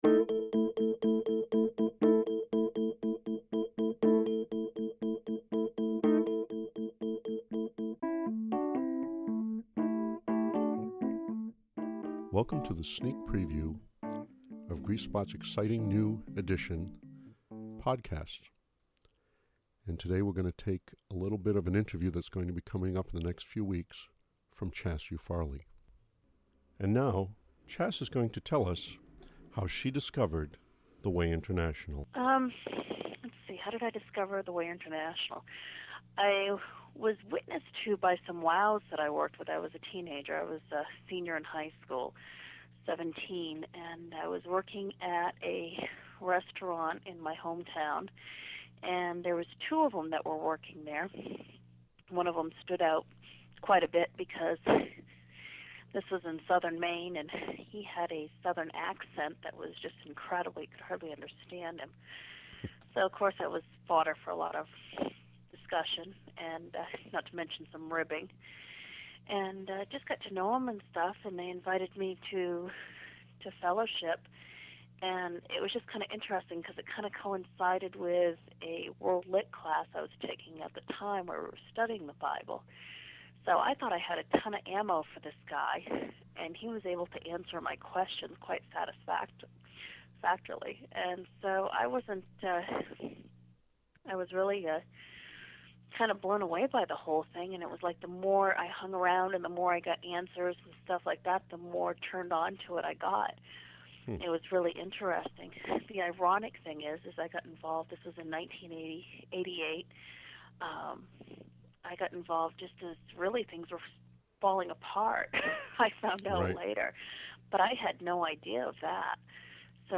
I am going to make it a show with some interesting features and have an interview as the main feature.
The Interviewer dropped his voice a few times near the end of sentences and it was a bit hard for me to understand what was said. But the Interviewer's voice is very easy to listen to and has a sense of sophisticated appeal.
What parts of the interview I get to hear are clear.
Crystal clear, gonna be a great interview, and is SOOOO cool to hear what people really sound like instead of my imagineered voices from their typed posts.
You two sound so professional and articulate.